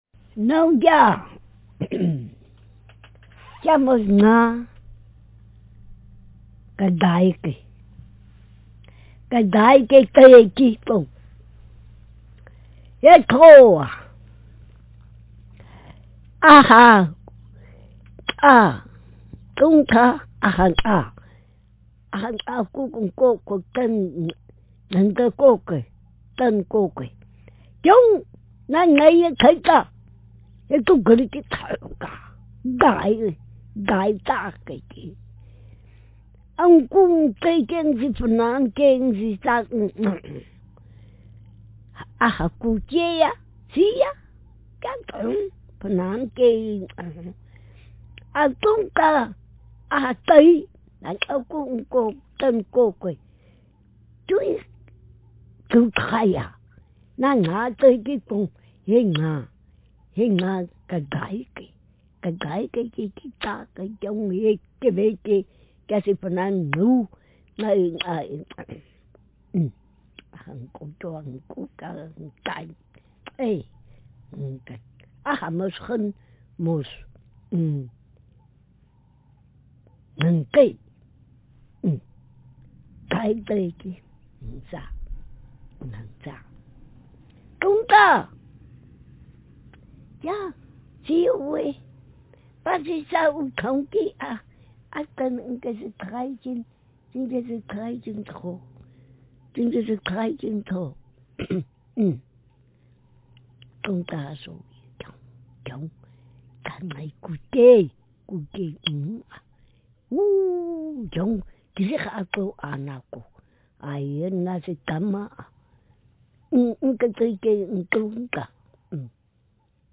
Speaker sex f Text genre traditional narrative